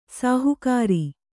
♪ sāhukāri